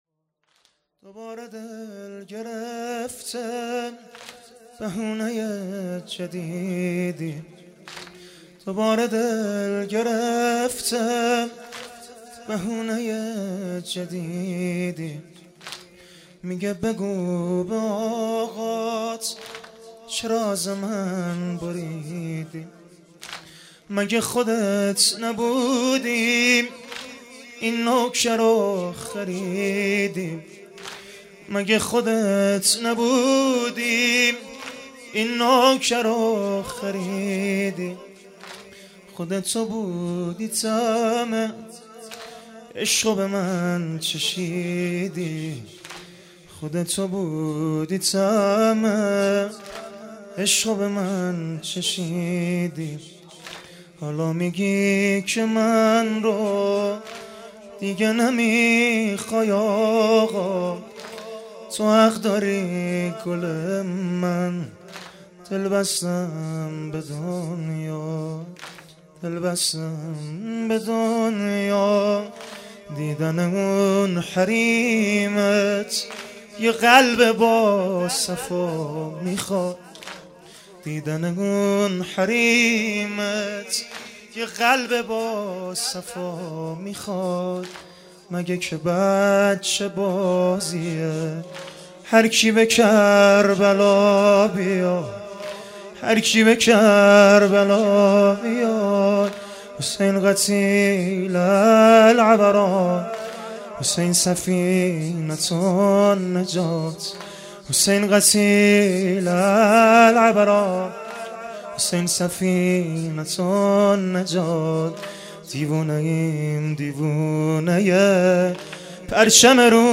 شب پنجم محرم 1396